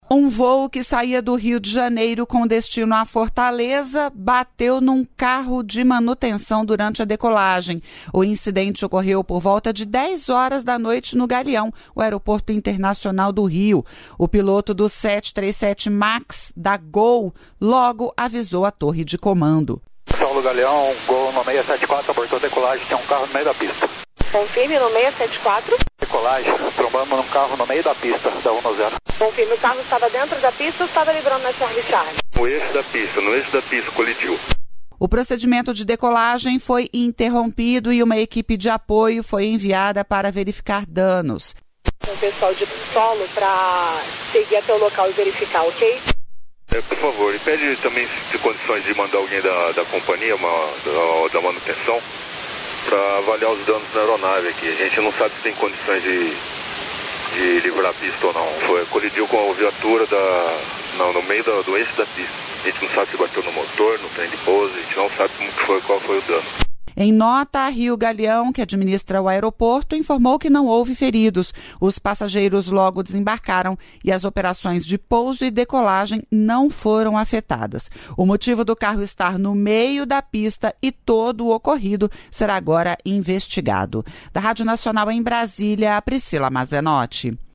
A declaração foi dada após o encontro com o presidente Lula, no Palácio do Planalto, e com o ministro da Fazenda, Fernando Haddad e o assessor especial para assuntos internacionais do Brasil, Celso Amorim.